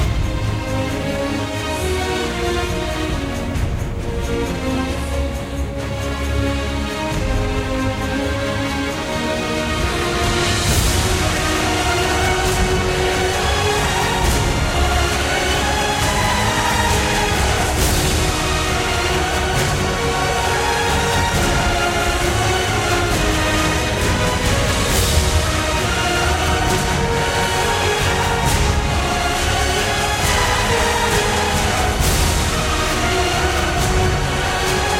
Trance Dance
Жанр: Танцевальные / Транс